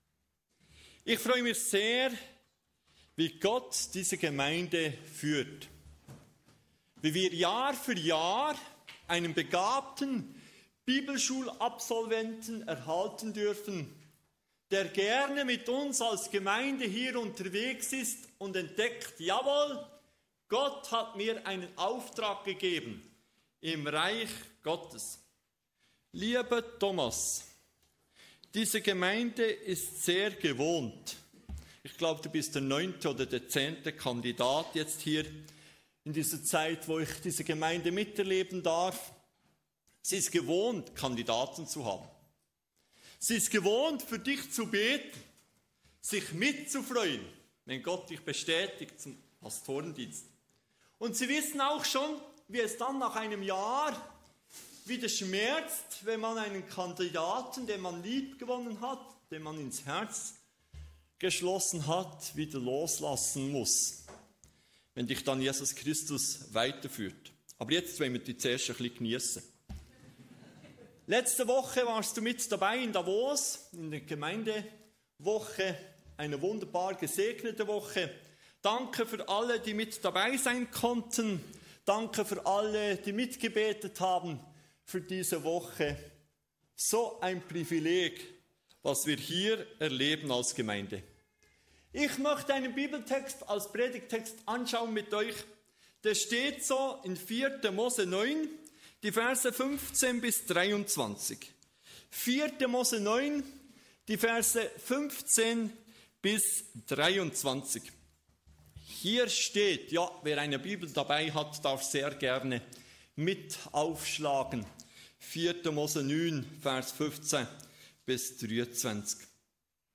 Was bedeutet es, heute der Wolken- und Feuersäule zu folgen? Diese Predigt zeigt, wie Gott sein Volk – damals wie heute – durch sein Wort führt: nicht durch Gefühle oder starke Persönlichkeiten, sondern durch seine Gegenwart.